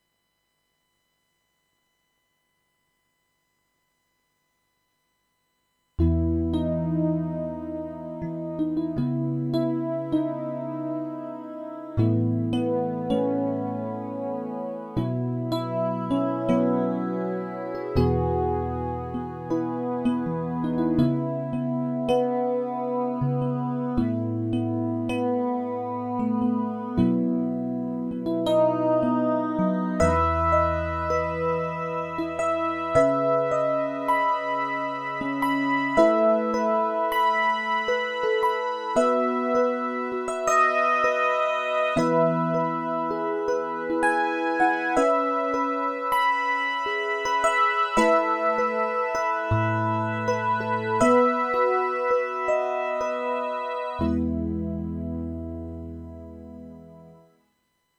mo-FM_2OP_Demo.mp3